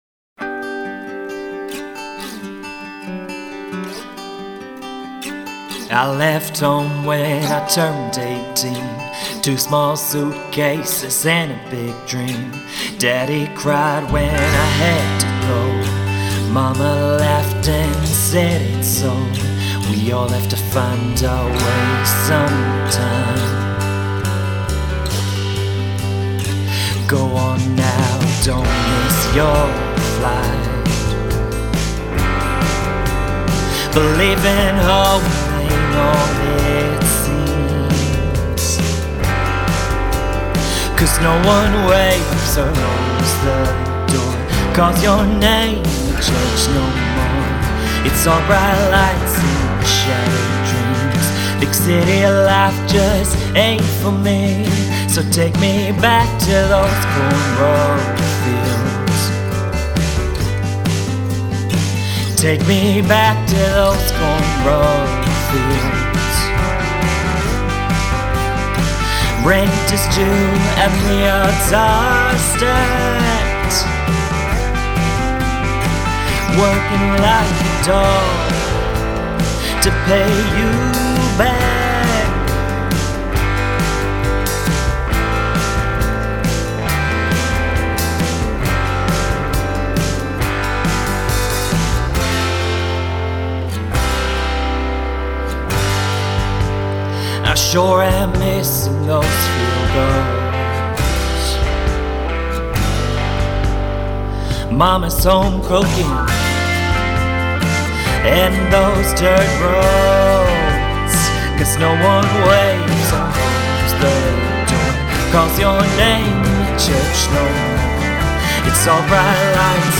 Having some troubles dropping this vocal into the instrumental track (comes in around 15 sec), aiming for a vintage, lo-fi sound (think old school Hank Williams recordings) but everything I'm getting just doesn't sound cohesive.